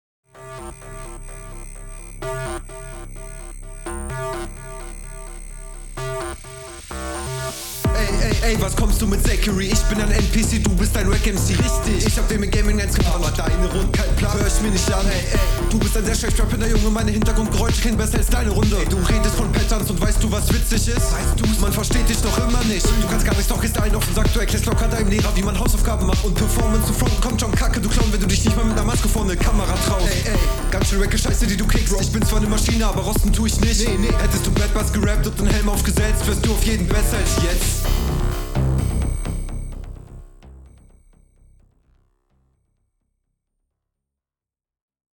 Also der flow ist hier teilweise schon bös nebendran und das klingt einfach nicht gut.
Flow: DU kommst viel besser auf dem Beat .